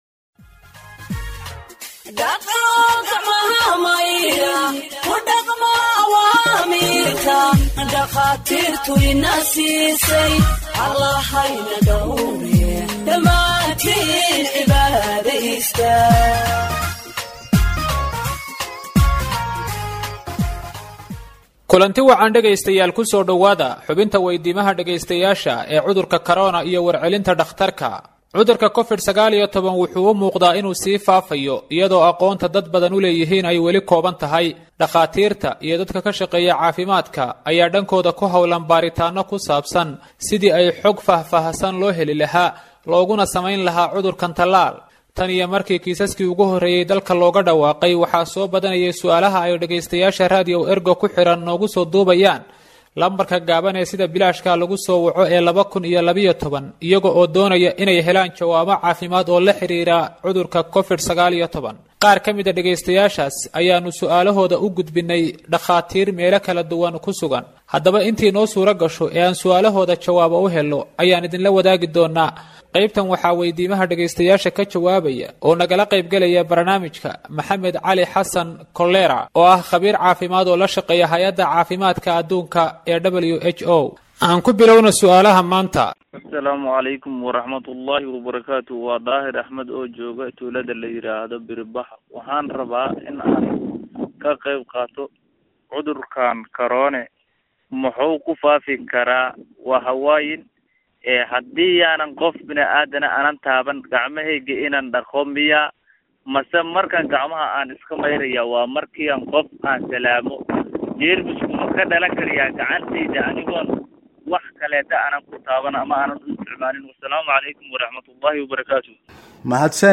Health expert answers listeners’ questions on COVID 19 (18)
Radio Ergo provides Somali humanitarian news gathered from its correspondents across the country for radio broadcast and website publication.